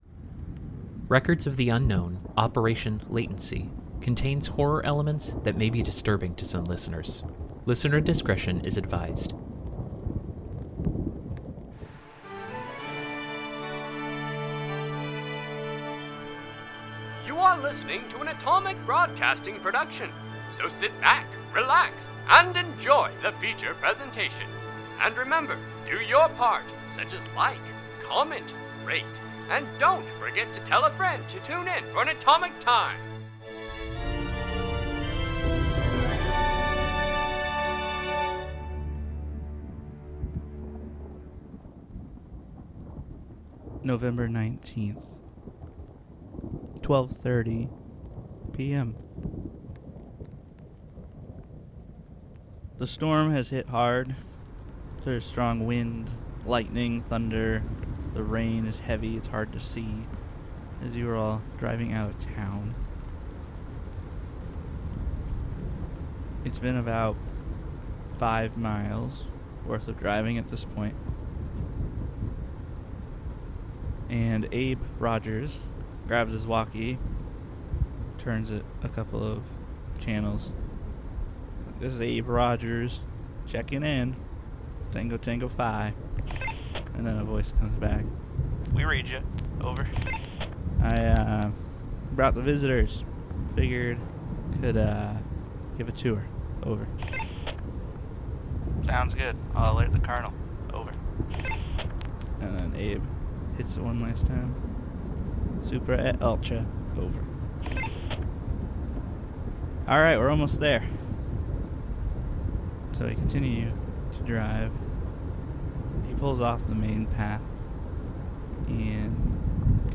Records of the Unknown is an unscripted improvisational podcast based on the game Delta Green by Arc Dream Publishing.